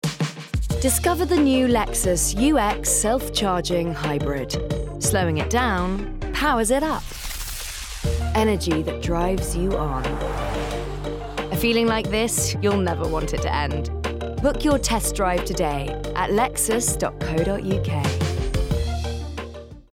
20/30's Neutral/London,
Assured/Engaging/Gravitas